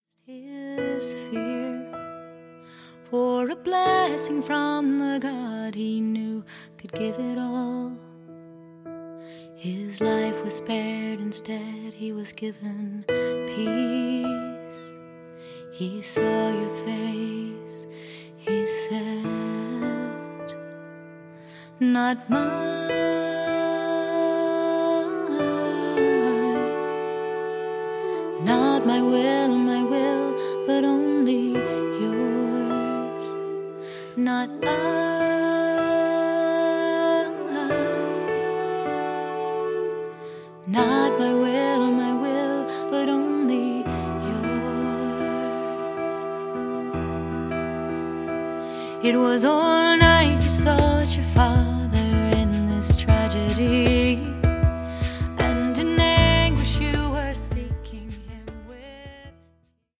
Verpackt in leichtfüßige Popmusik mit Celtic-Folk-Einflüssen